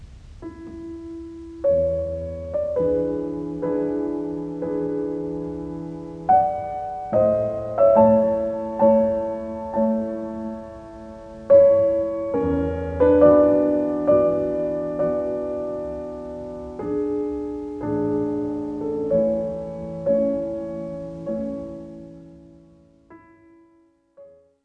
録音に使用したのは、当方で所有するカセットテープの中で最も当時のスペックに近いと思われるTDKのD60（ノーマルテープ・1980年頃購入）を使用しています。（写真左）
録音形式は22.05kHz・16bit・ステレオ、収録時間は約２５秒です。
内蔵サウンド機能のクオリティが非常に悪質なので（こんなに悪いとは思わなかった）、今回の比較試聴では音質評価（絶対評価）ではなく、それぞれの方式による収録音の比較相対評価でお願いします。
adres方式による録音です。
ヒスノイズレベルはｄｂｘ方式に劣るものの、ブリージングノイズはほとんど判別できません。ヒスノイズも高域成分が抑えられており、聴感ノイズが緩和されています。